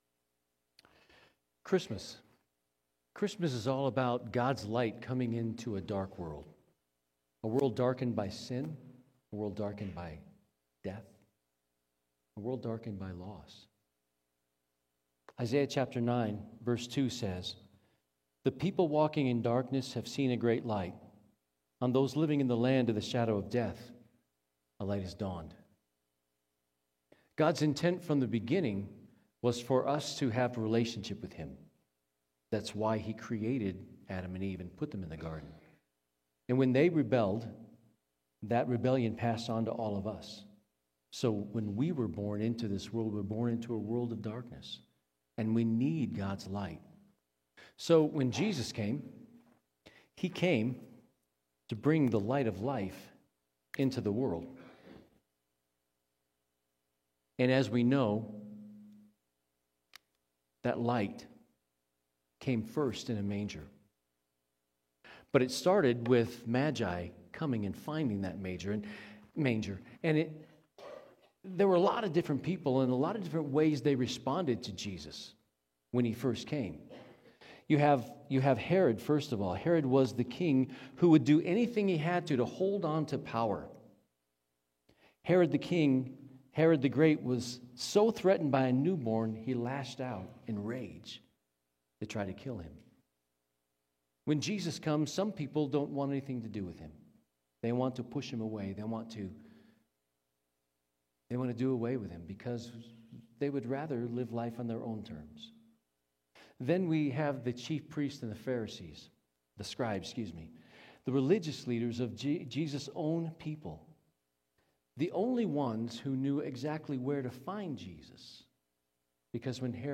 Candlelight Service
Download Download Immanuel: The Wonder of God With Us Current Sermon Candlelight Service Immanuel